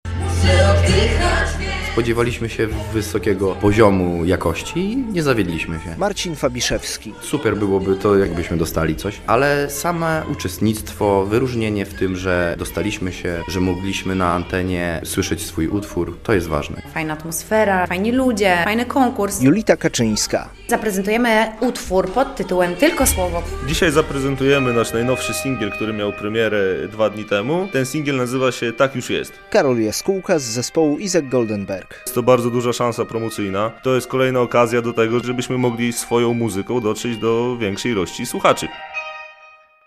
Kilku finalistów Niebieskiego Mikrofonu już po pierwszej próbie - relacja